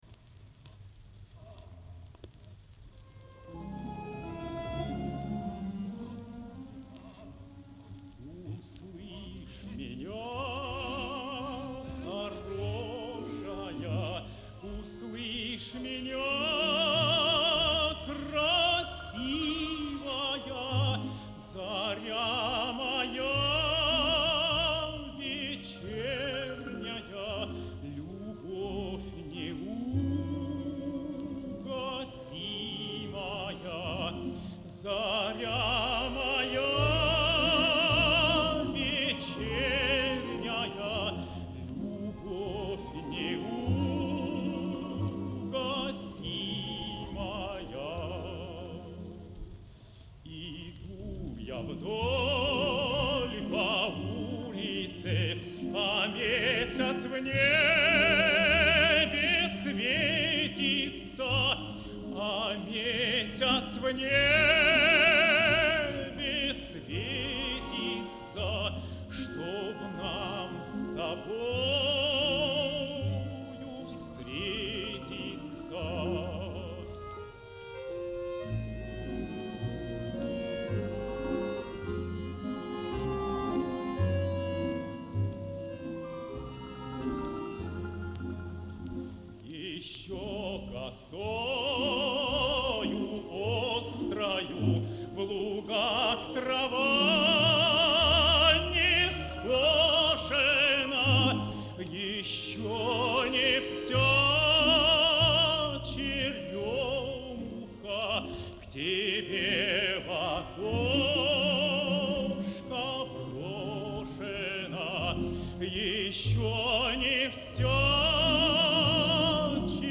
Шедевр советской лирики.